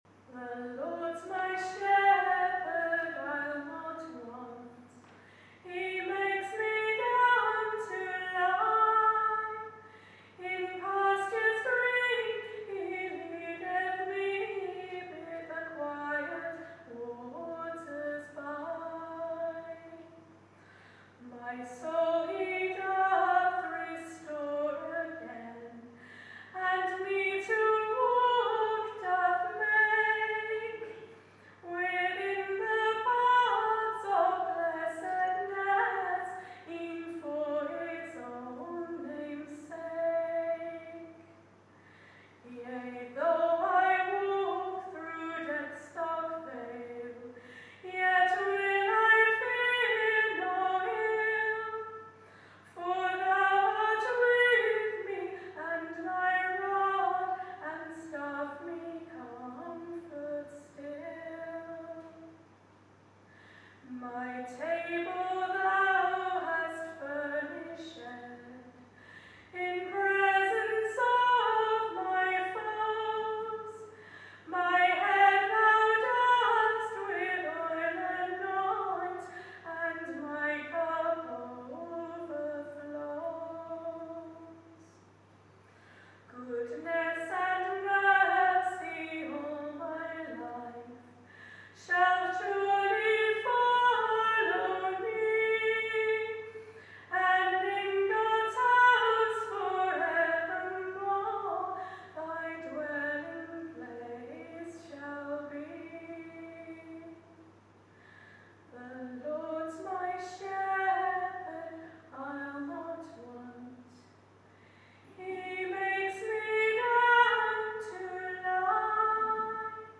I sing The Lord's My Shepherd to the tune of Searching for Lambs at a Christ the King Sunday service on 26 November 2023